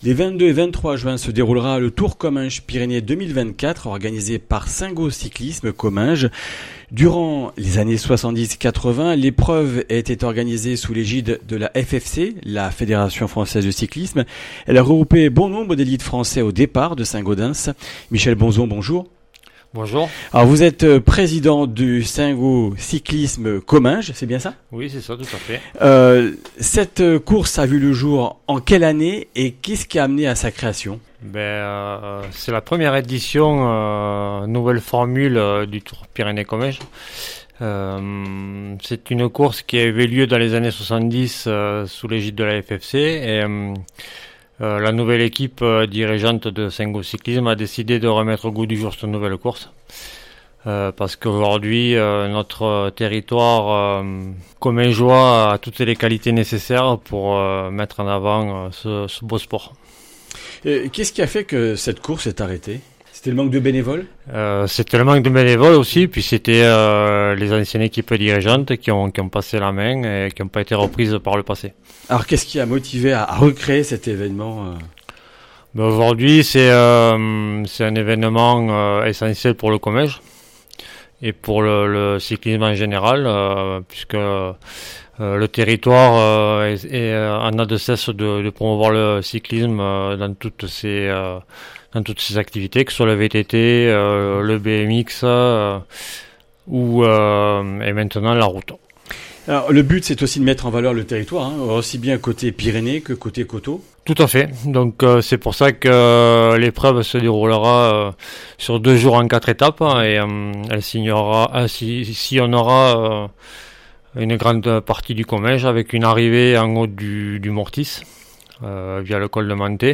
jeudi 20 juin 2024 Le grand entretien Durée 10 min